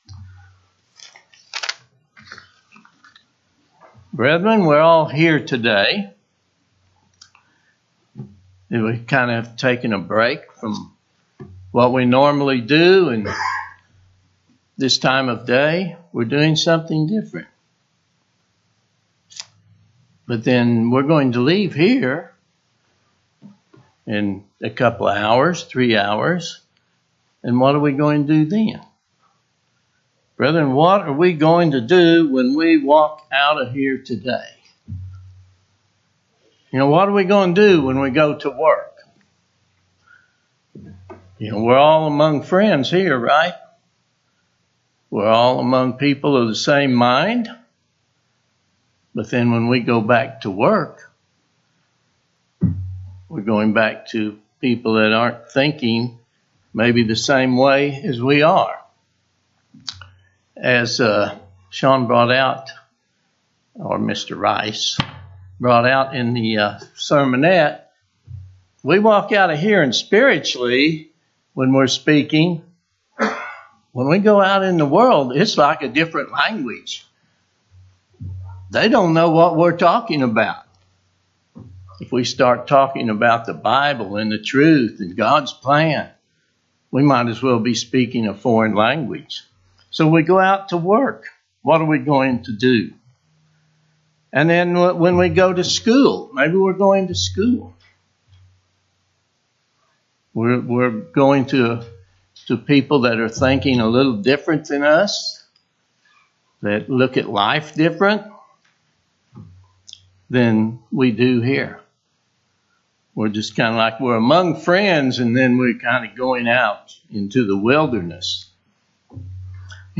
Given in Oklahoma City, OK